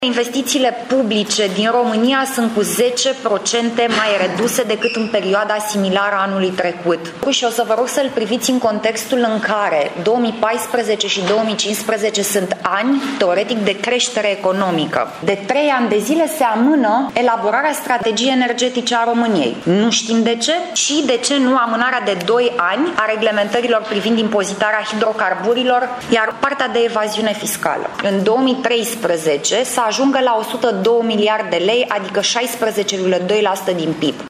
În plus, declară copreședintele PNL, Alina Gorghiu, programele de infrastructură au fost amânate sau reduse: